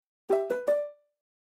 Уведомления, оповещения, сообщения гугл почты Gmail в mp3